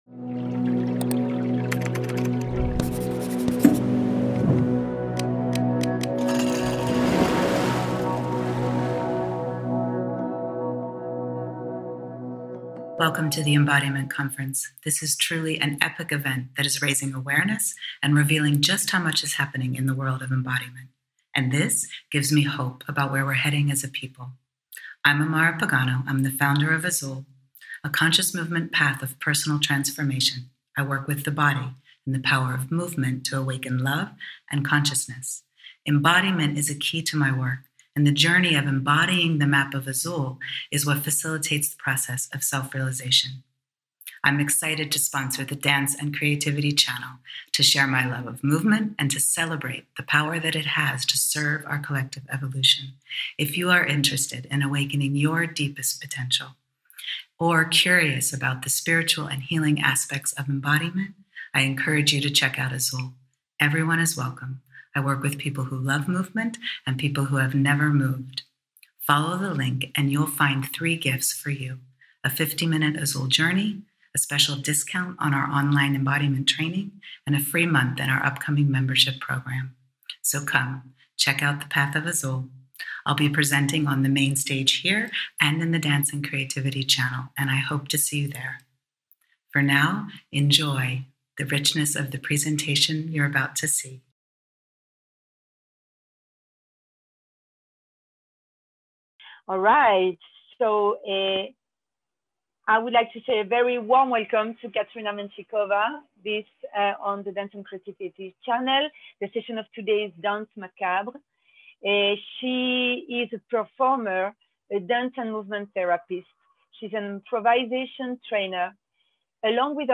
Music gives space and depth to embrace the inevitable.
This session combines guided and free movement, open discussion and questions.